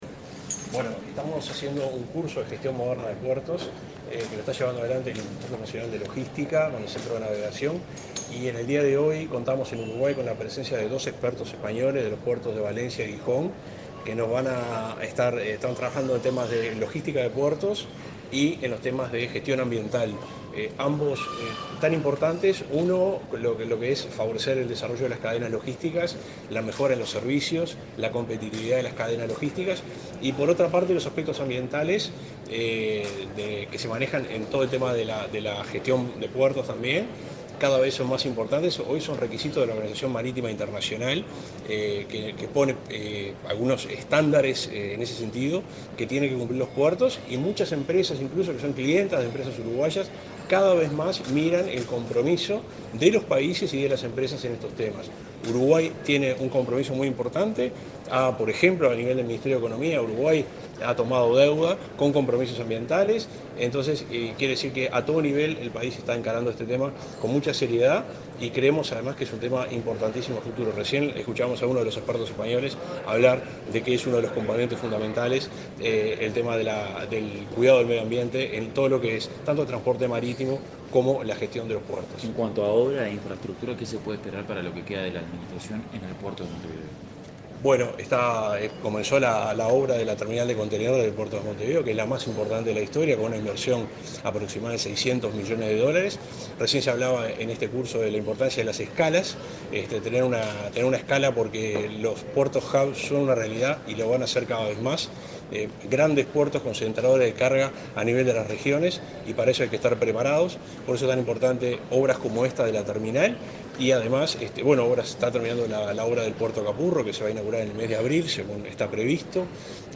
Declaraciones del subsecretario de Transporte
El subsecretario de Transporte y Obras Públicas, Juan José Olaizola, dialogó con la prensa, este miércoles 29 en Montevideo, luego de disertar sobre